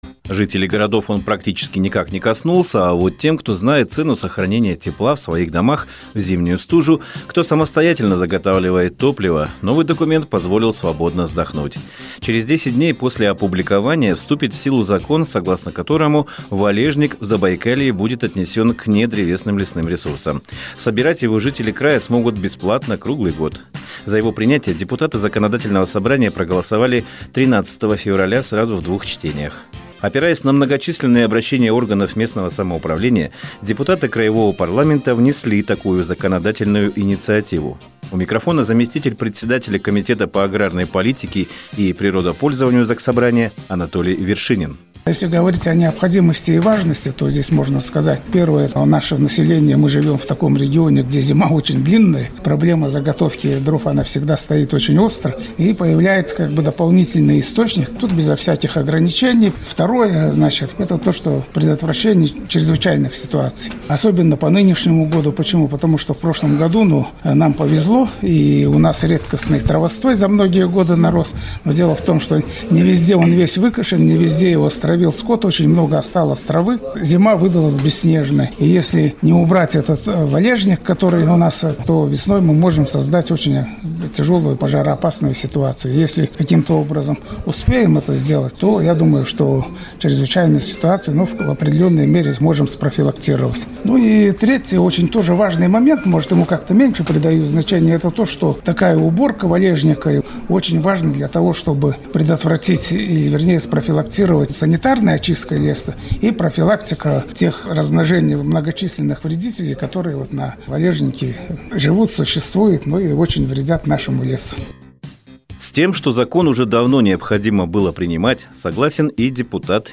Закон, которого ждали. В эфире «Радио России» Чита депутаты краевого парламента объяснили жителям края особенности закона о сборе валежника.